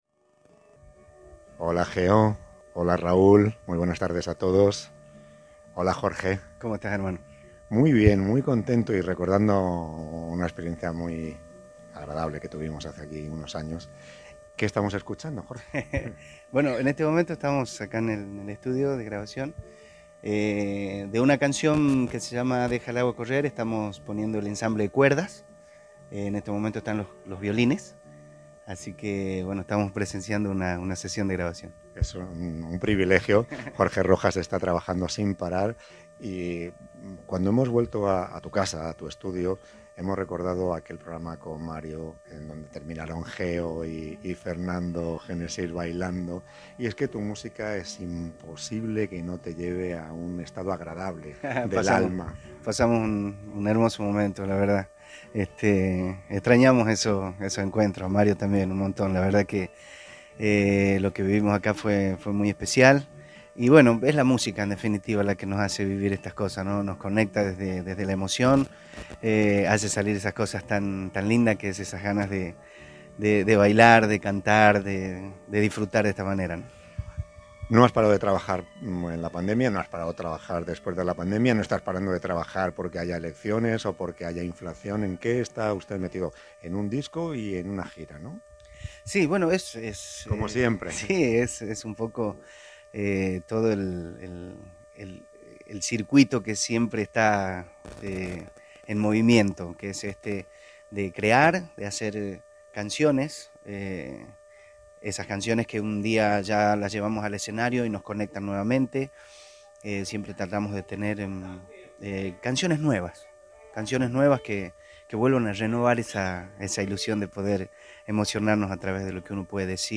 Una vez más, Jorge Rojas le abrió las puertas de su casa y de su estudio de grabación a Cadena 3.